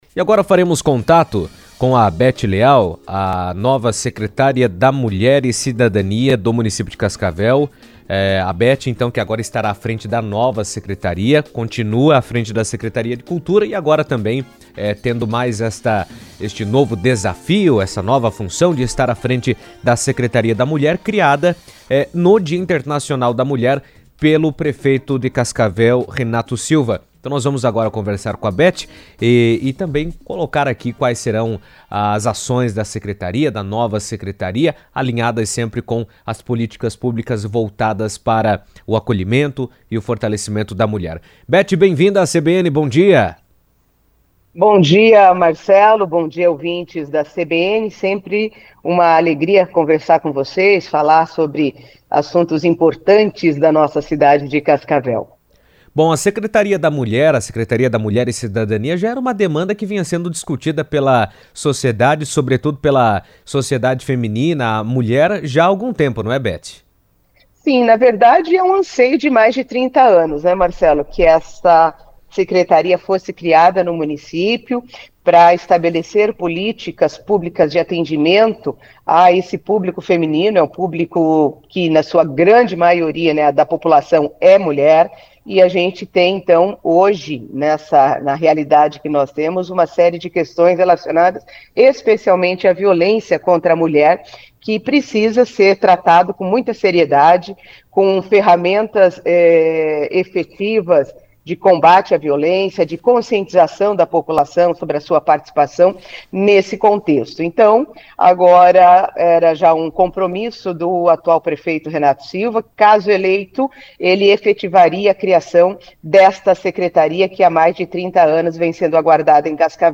O município de Cascavel passou a contar com a Secretaria da Mulher, criada com o objetivo de ampliar políticas públicas voltadas à proteção, acolhimento e empoderamento feminino. Em entrevista à CBN, a secretária Beth Leal destacou que a iniciativa pretende fortalecer os serviços já existentes, criar novas ações de apoio às mulheres em situação de vulnerabilidade e promover programas de prevenção à violência de gênero.